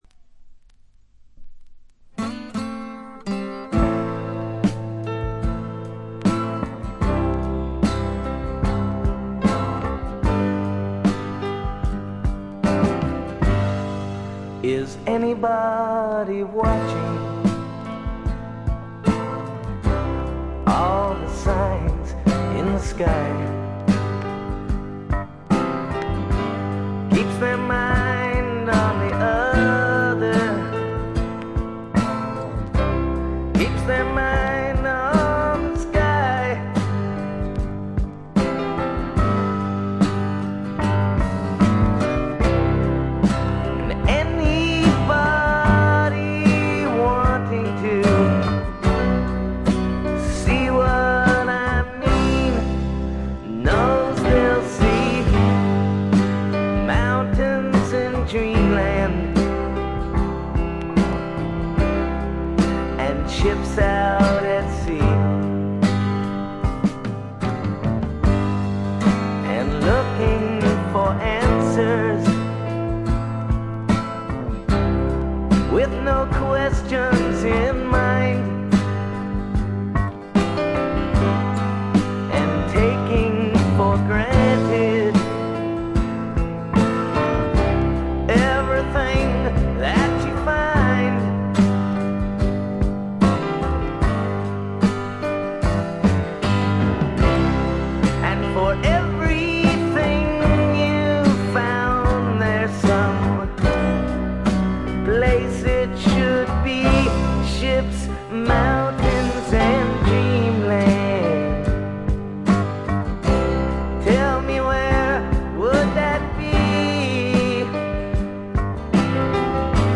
わずかなノイズ感のみ。
渋みのあるヴォーカルも味わい深い88点作品。
試聴曲は現品からの取り込み音源です。